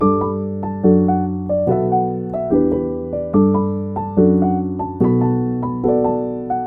罗非式环路 花
Tag: 144 bpm LoFi Loops Piano Loops 1.12 MB wav Key : Unknown FL Studio